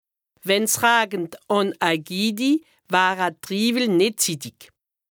Haut Rhin
Ville Prononciation 68